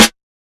kits/OZ/Snares/SN_Love.wav at main
SN_Love.wav